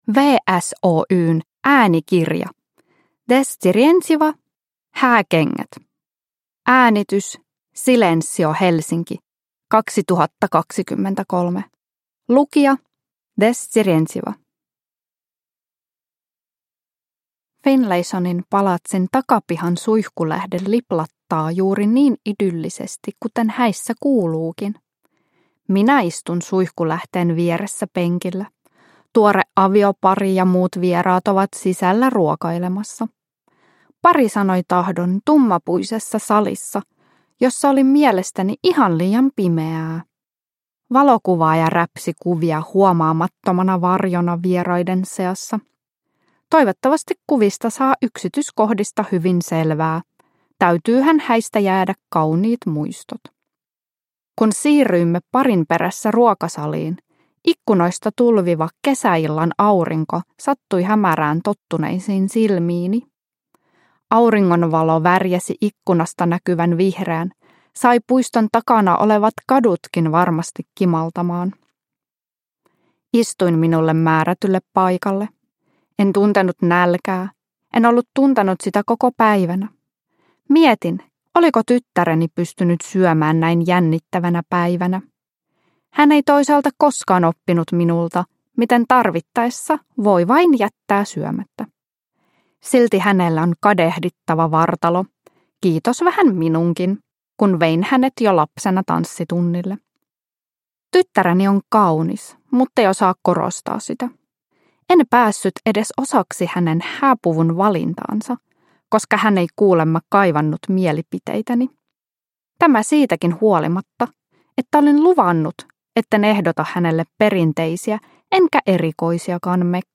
True love: Hääkengät – Ljudbok – Laddas ner